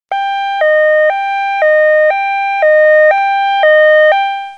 AmbulanceAc.mp3